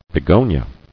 [be·go·nia]